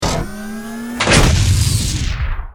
battlesuit_handcannon.ogg